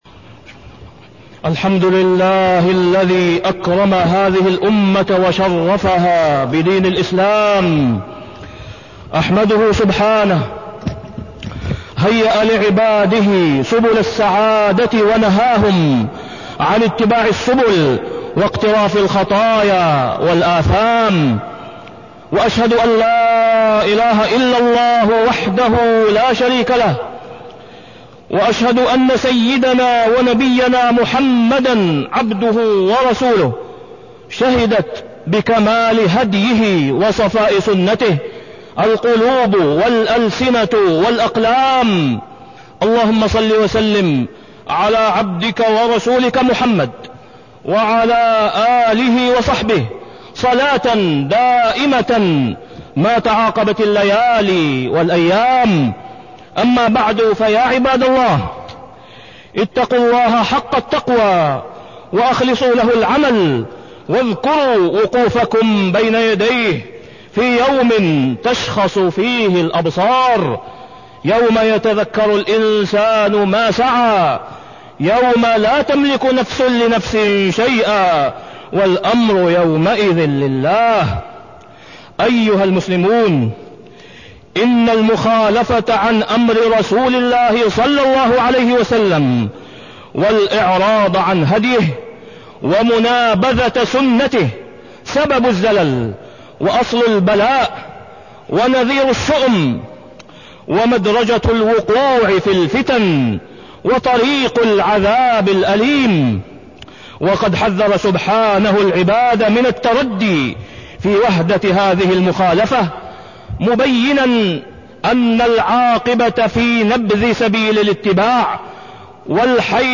تاريخ النشر ٢٢ ربيع الأول ١٤٢٤ هـ المكان: المسجد الحرام الشيخ: فضيلة الشيخ د. أسامة بن عبدالله خياط فضيلة الشيخ د. أسامة بن عبدالله خياط الرسول الأسوة